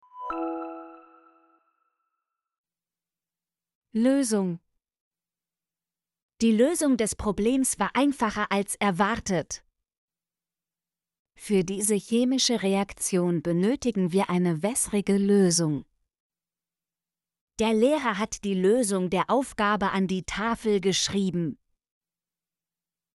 lösung - Example Sentences & Pronunciation, German Frequency List